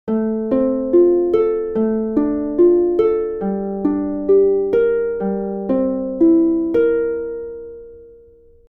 The right hand finger sequence of p-i-m-a is played twice in each measure of 4/4 meter, resulting in a continuous flow of eighth notes.
Here's what P-i-m-a 2X looks and sounds like when applied to the four chords in Betty Lou of '52: